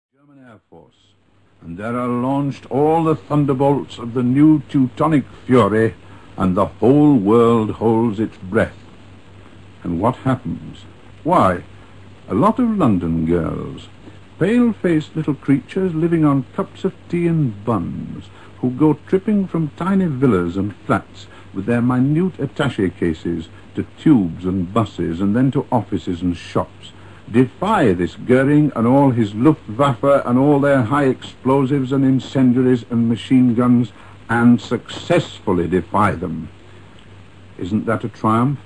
June 5  J B Priestley makes the first of his Postscript broadcasts on BBC radio.
Listen Extract from typical talk [Source: BBC]